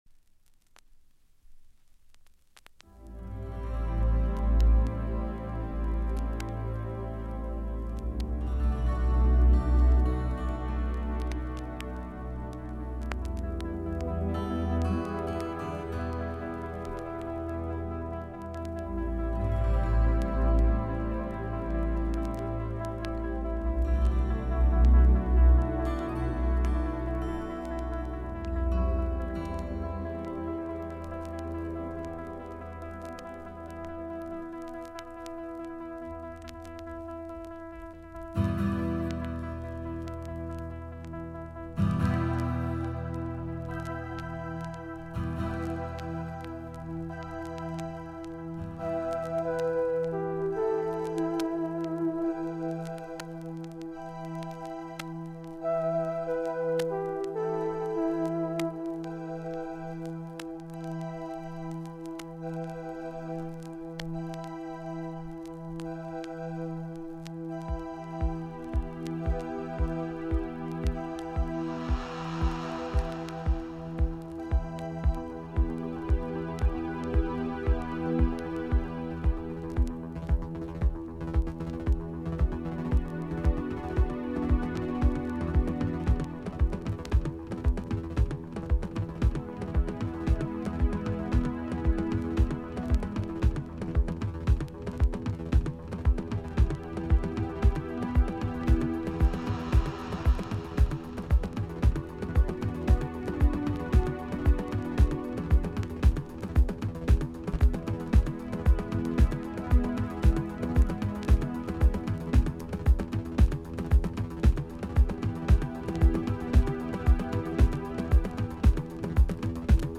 All vinyl for about an hour.